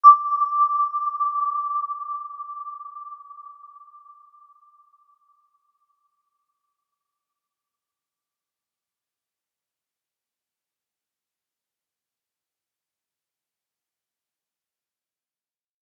Aurora-E6-p.wav